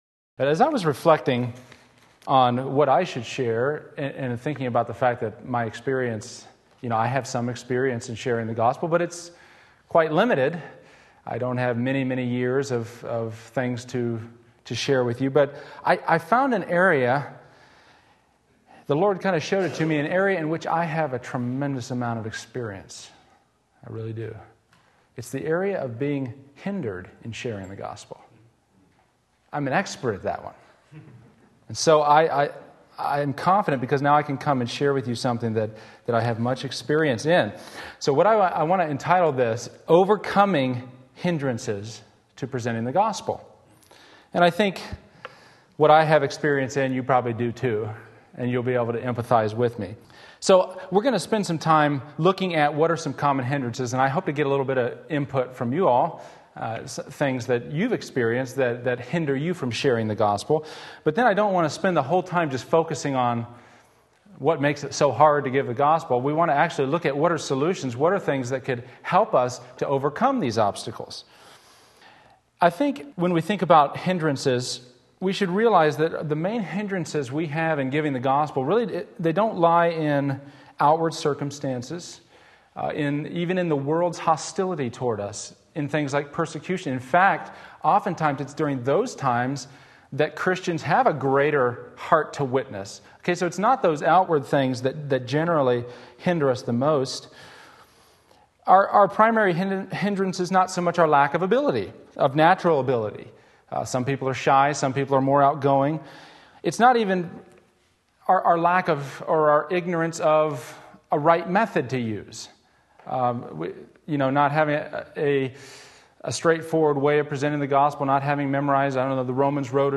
Sermon Link
Overcoming Hindrances to Sharing the Gospel various texts Wednesday Evening Service